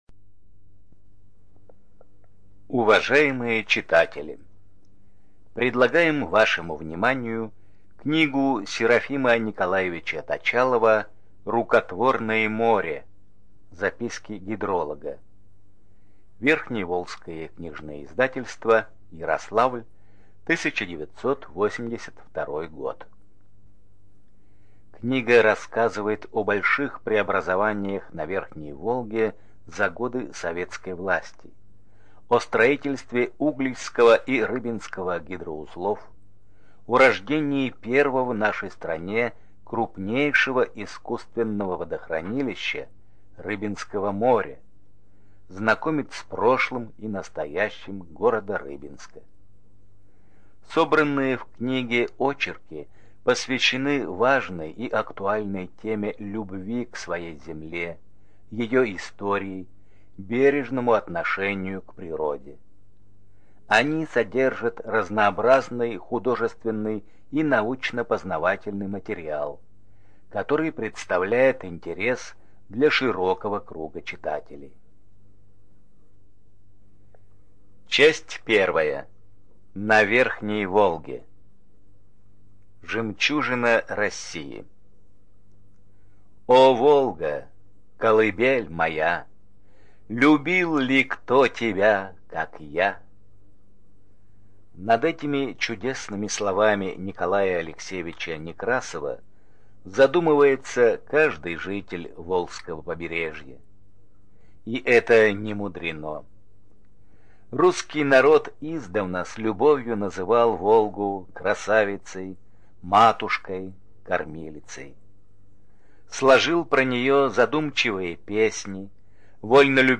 Студия звукозаписиГоворящая книга Рыбинска